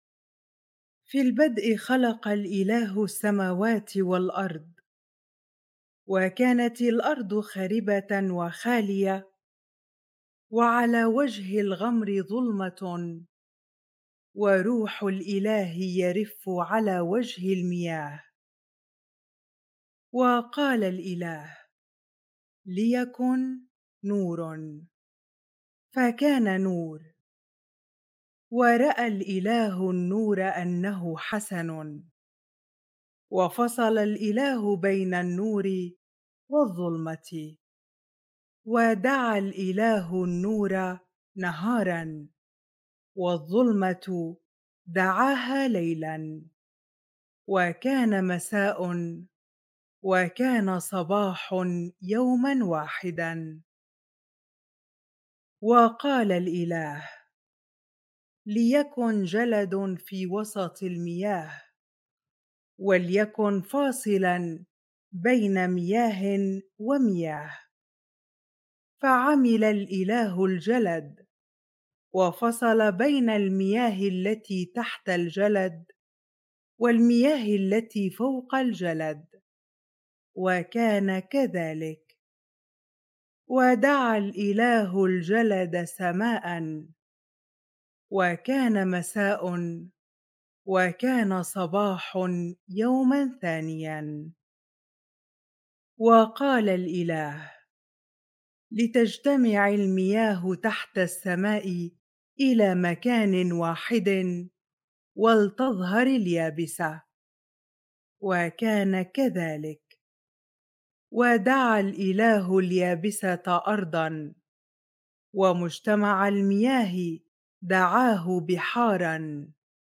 Bible reading